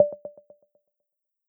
progress_loop.wav